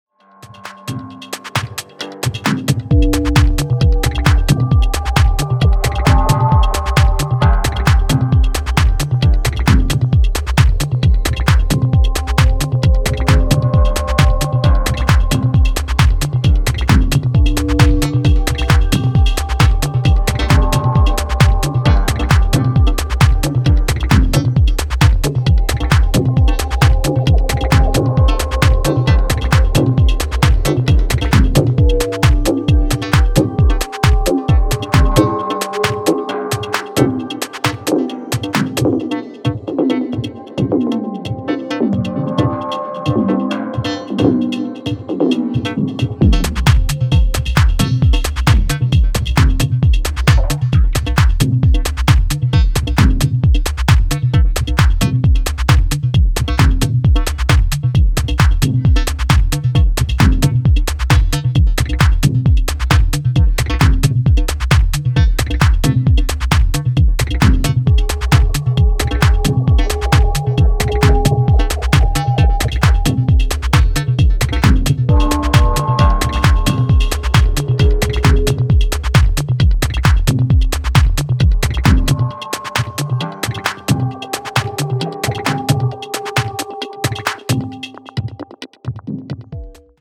強力なサブベースが支配する空間にシャープに切り込むスネア/ハットが特徴のダビーミニマルローラー”Saence”。